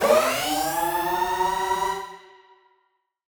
Index of /musicradar/future-rave-samples/Siren-Horn Type Hits/Ramp Up
FR_SirHornA[up]-G.wav